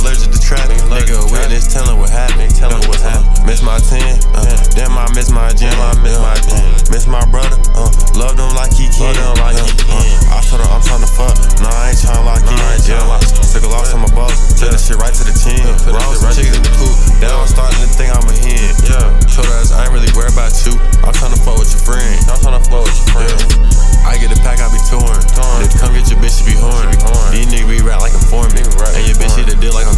Жанр: Хип-Хоп / Рэп / Танцевальные